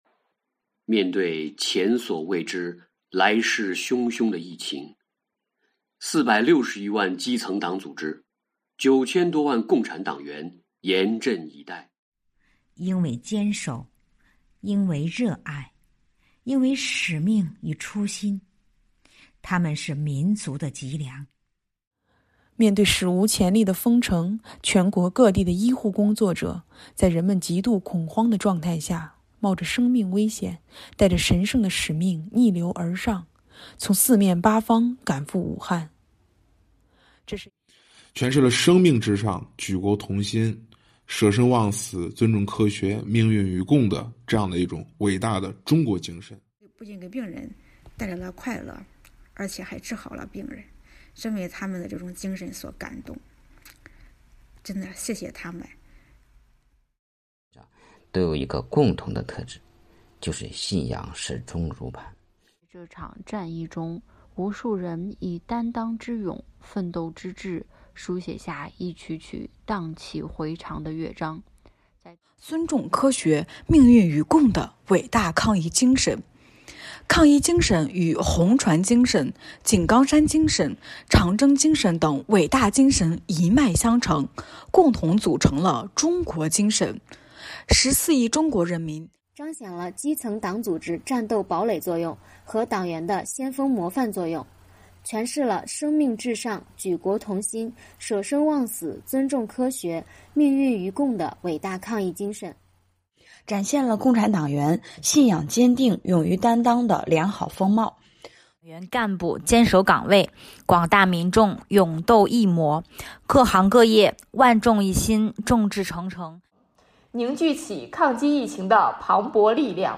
马克思主义学院党总支全体党员在收看之后集中交流研讨了榜样的事迹，每人录制一段音频，以朗诵或演讲的形式，讲述了自身观看之后的所思所感。